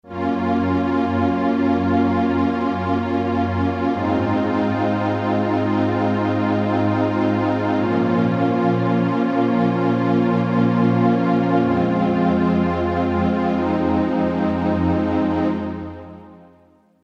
demo pad 2
pad2.mp3